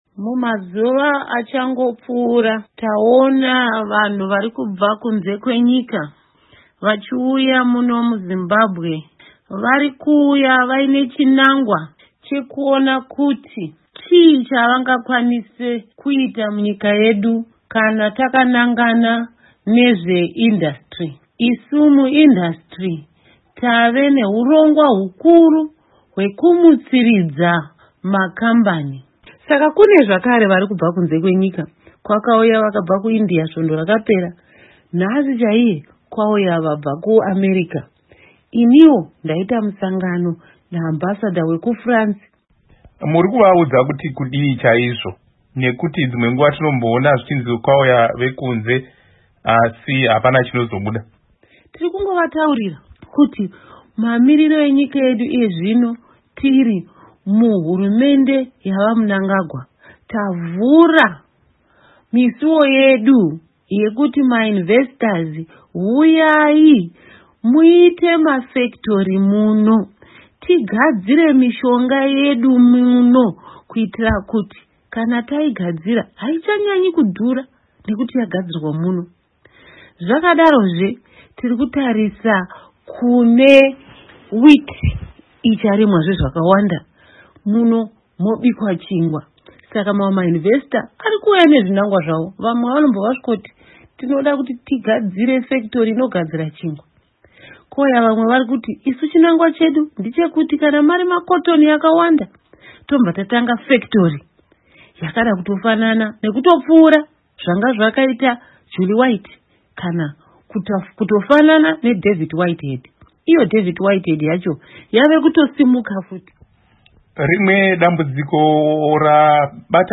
Hurukuro naDoctor Sekai Nzenza